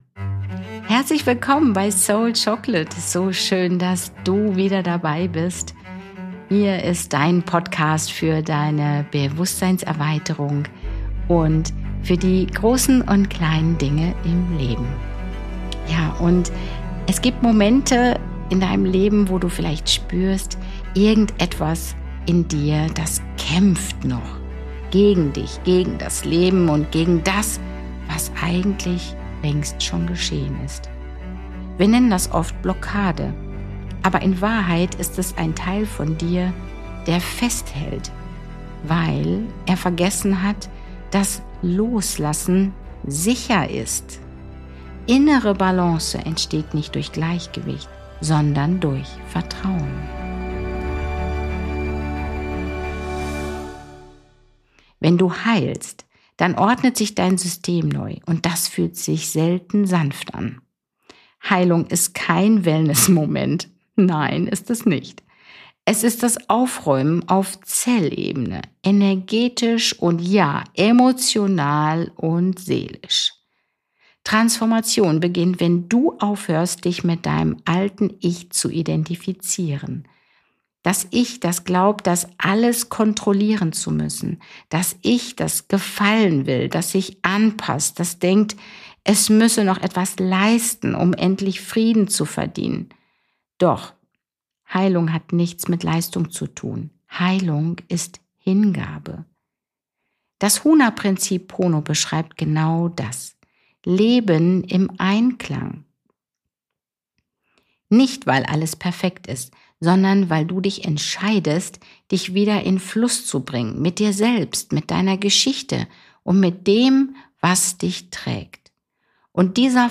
Eine ruhige, kraftvolle Folge über Vertrauen,